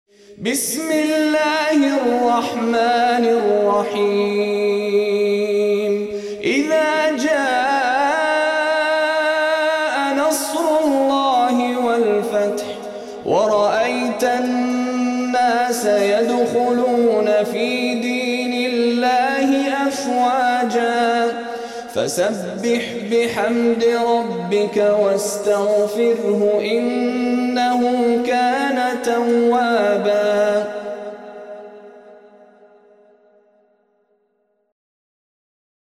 منتخب تلاوتهای شیخ مشاری العفاسی